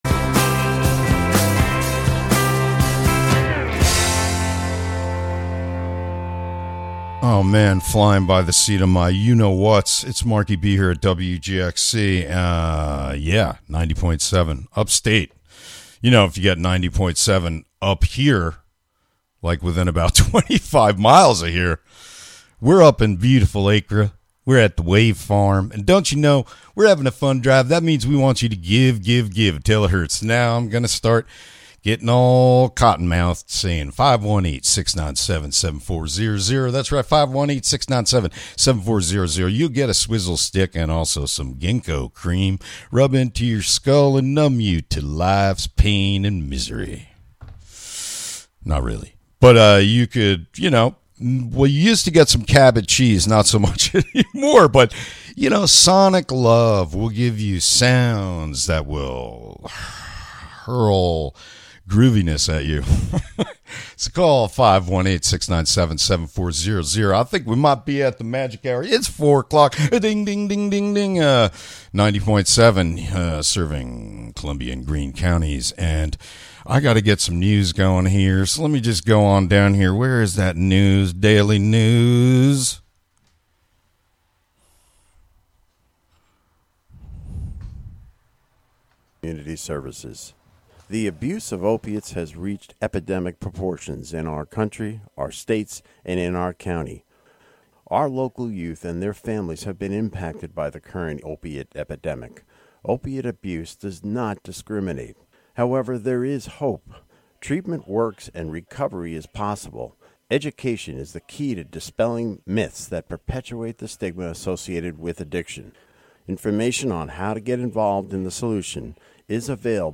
Day Three of WGXC's Radio Everywhere! pledge drive continues, live from the Acra studio at the Wave Farm Study Center. The WGXC Afternoon Show is a radio magazine show featuring local news, interviews with community leaders and personalities, a rundown of public meetings, local and regional events, with weather updates, and more about and for the community.